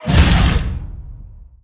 Sfx_creature_bruteshark_flinch_03.ogg